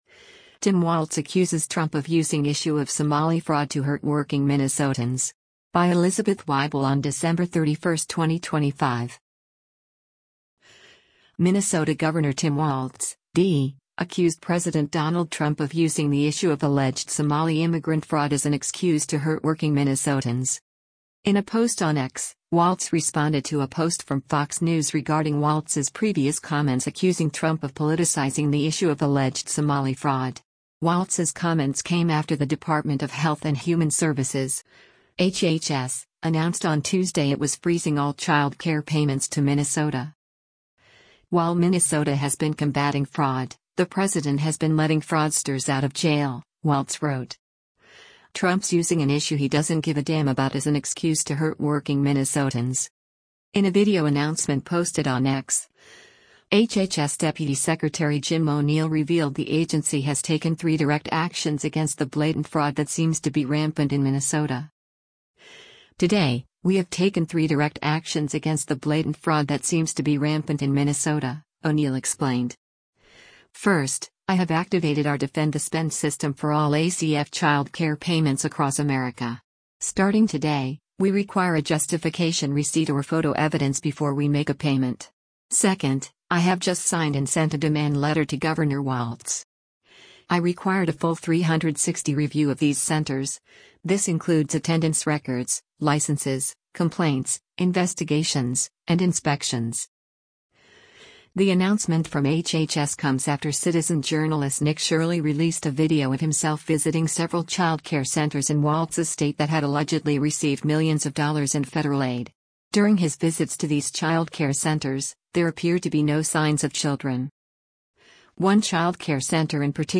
In a video announcement posted on X, HHS Deputy Secretary Jim O’Neill revealed the agency has “taken three direct actions against the blatant fraud that seems to be rampant in Minnesota.”